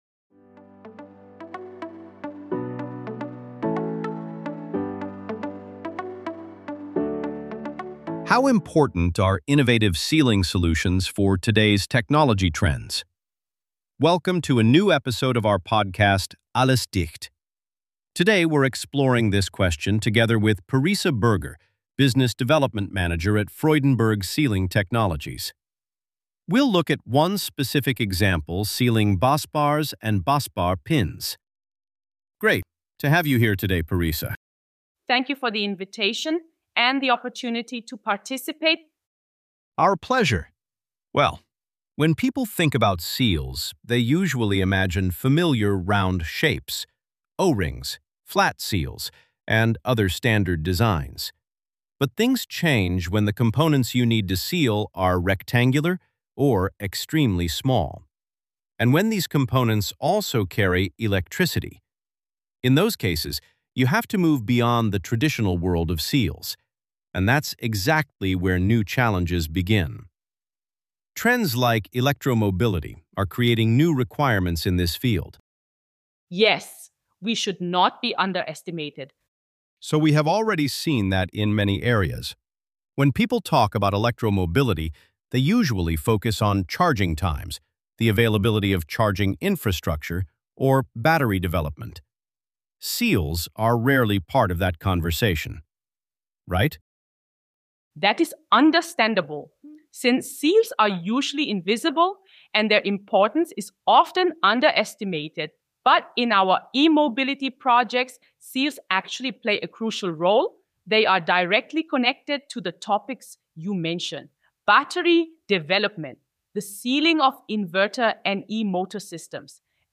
We used AI-generated voices in this episode, and one of them is a cloned version of a real person’s voice – with their permission.